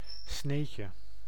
Ääntäminen
France: IPA: /ba.lafʁ/